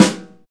Index of /90_sSampleCDs/Roland LCDP03 Orchestral Perc/SNR_Orch Snares/SNR_Dry Snares
SNR SNORCH 3.wav